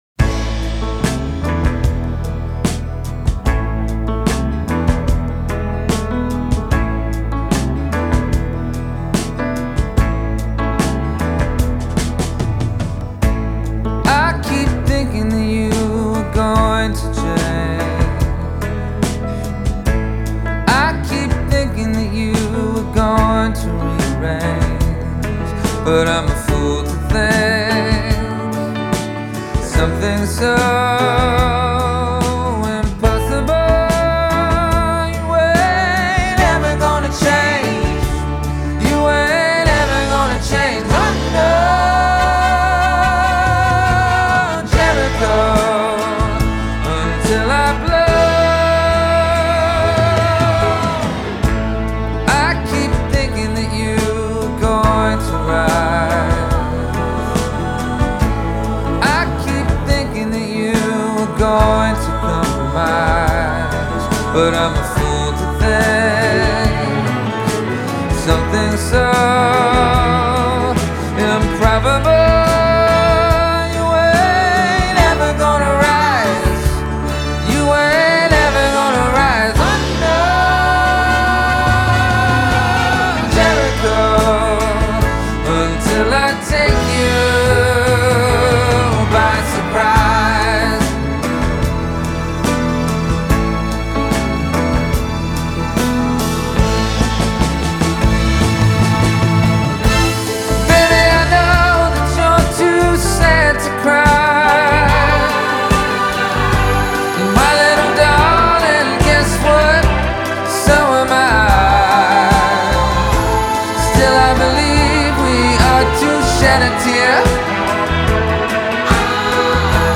with his mournful pitch perfect warble
a tour de force of an arrangement